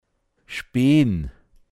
Wortlisten - Pinzgauer Mundart Lexikon
Kalb der Mutterkuh entwöhnen spee(n)n